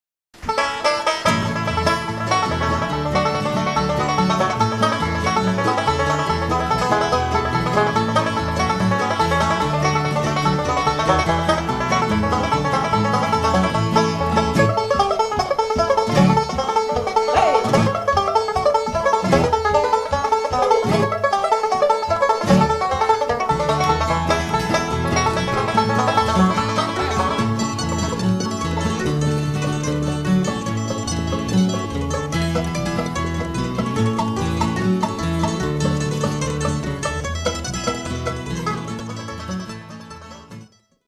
ブルーグラスの響き
しかも、その音色のきれいなこと。
マンドリンとウッドベースも達者な演奏で、全く脱帽でした。